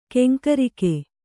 ♪ keŋkarike